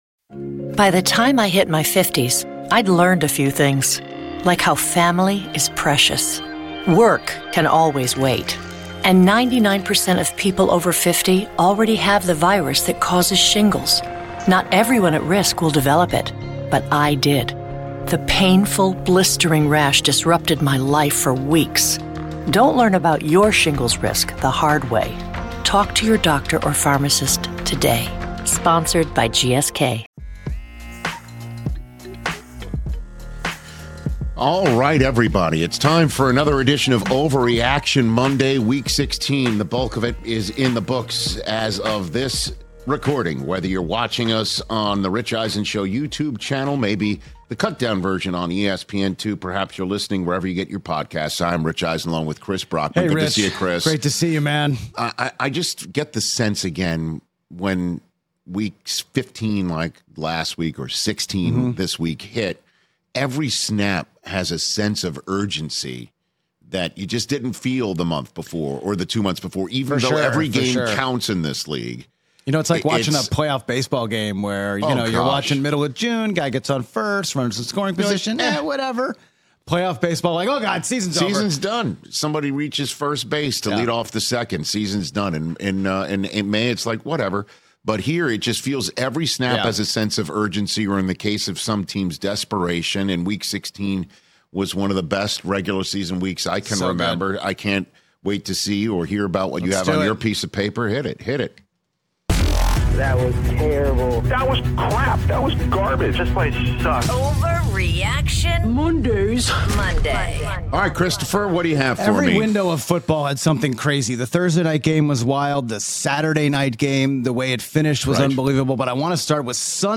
debating the latest in the NFL